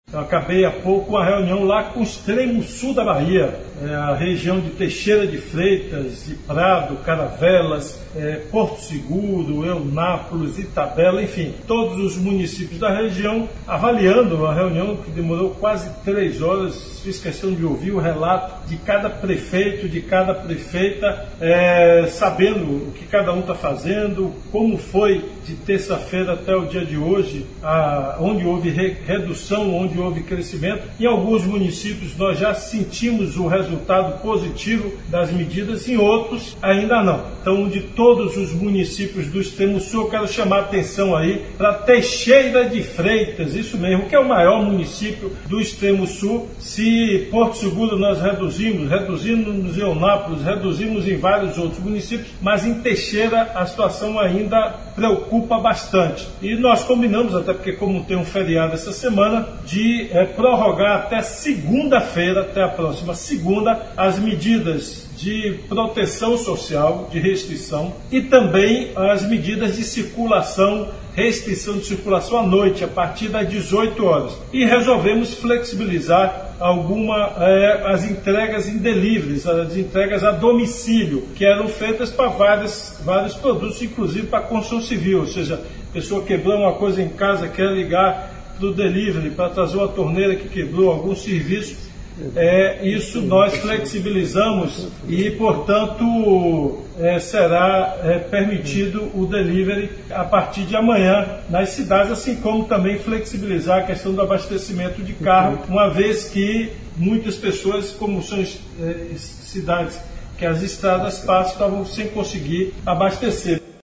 Segue áudio do governador Rui Costa explicando a decisão